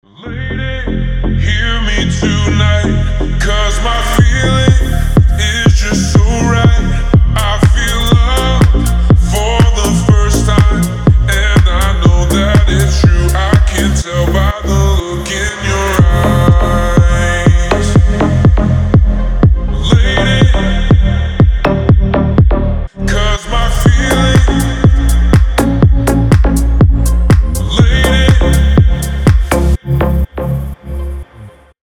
• Качество: 320, Stereo
мужской голос
deep house
retromix
future house